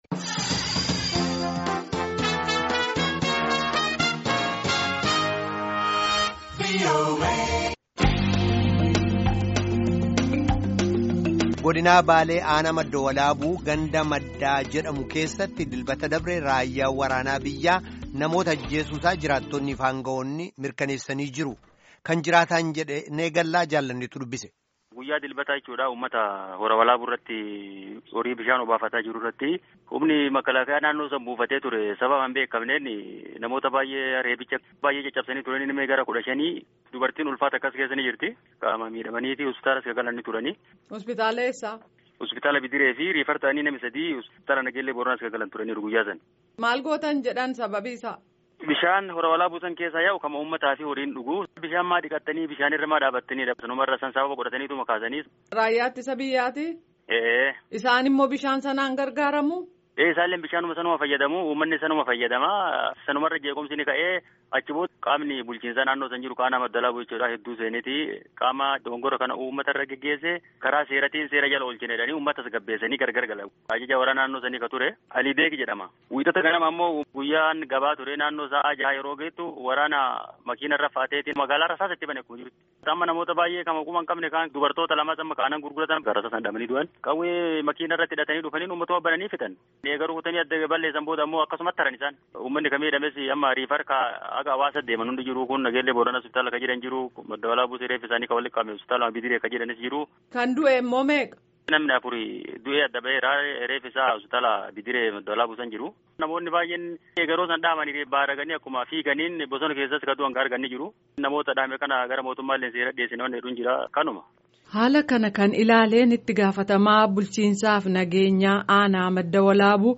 Gaaffii fi Deebii Caqasaa